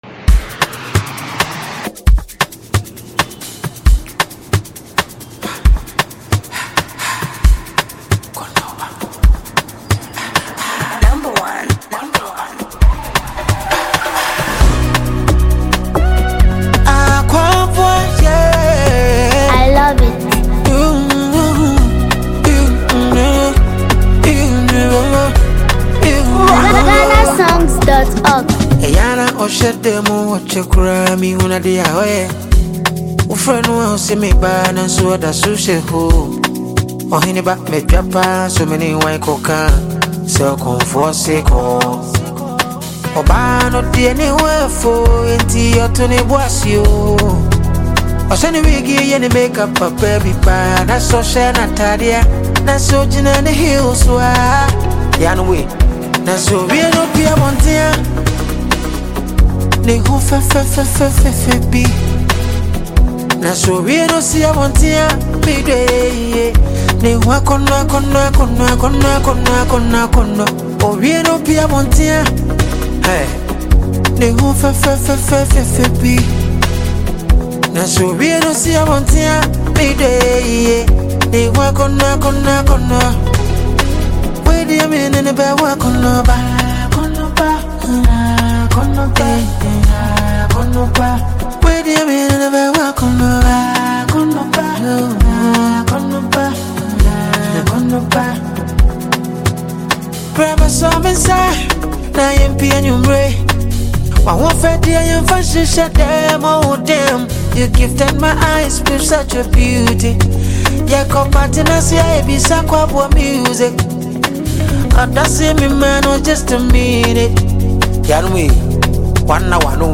Ghanaian highlife and soul
heartfelt and emotionally rich song
smooth melodies